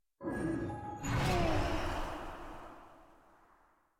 sfx-pm-level-unlock-2.ogg